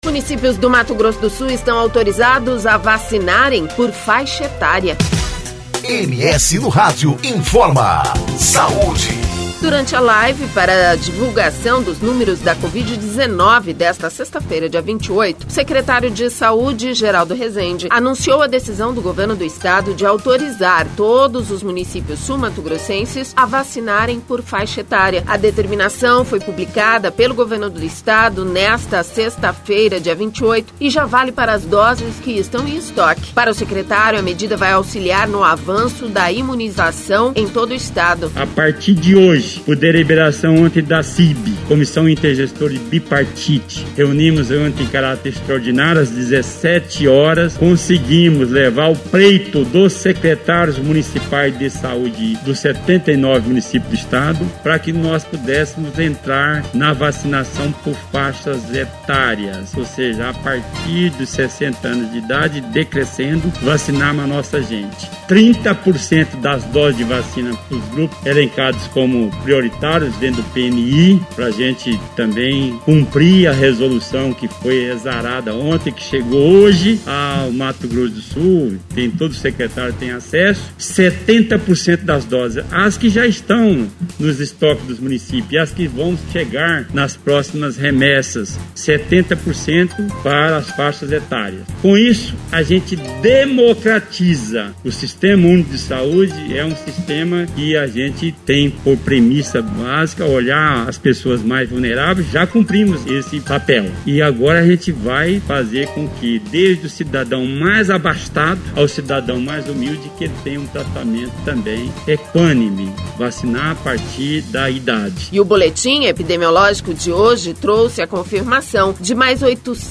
Durante a live para divulgação dos números da Covid-19, desta sexta-feira, dia 28, o secretário de saúde, Geraldo Resende anunciou a decisão do Governo do Estado, de autorizar todos os municípios sul-mato-grossenses a vacinarem por faixa etária.